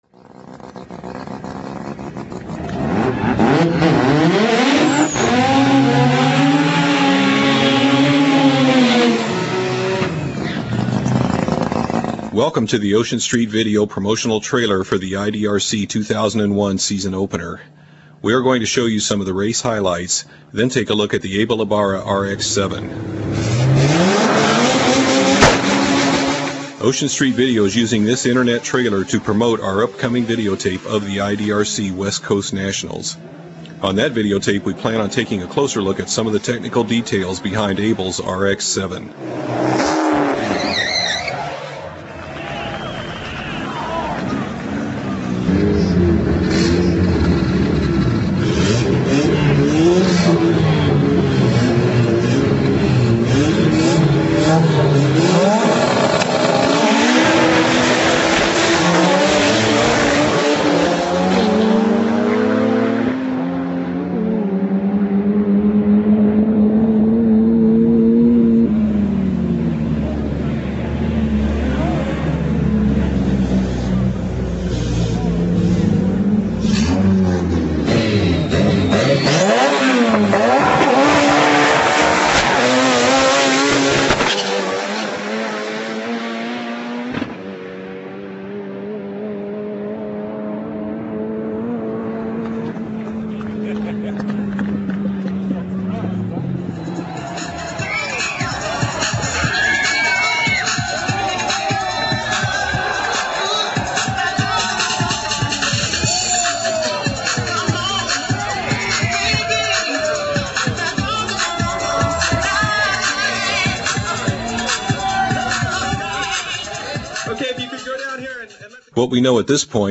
Click to see and hear a 7.5 second, 175 mph Mazda RX-7 lose control!
Here is a look at the IDRC West Coast Nationals at Palmdale, CA.